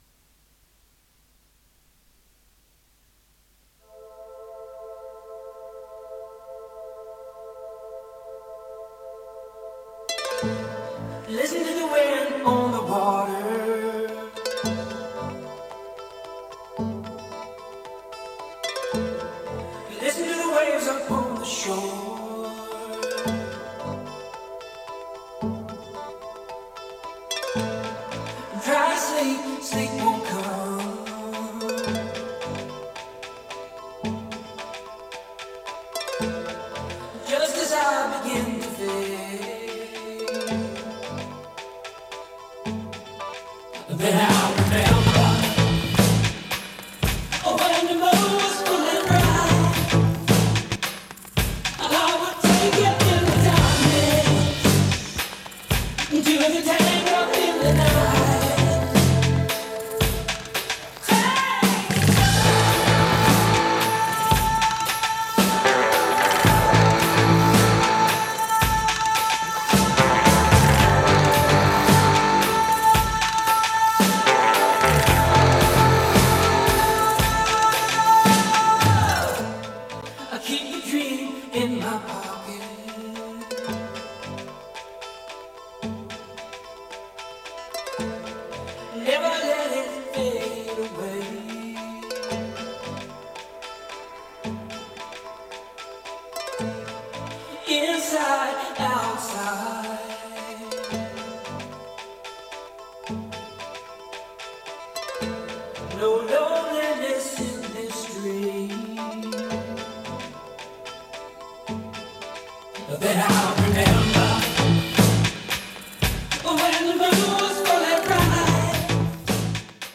Below a recording made by the SD1015R and played back by it:
Type: 2-head, single compact cassette deck
Track System: 4-track, 2-channel stereo
Noise Reduction: B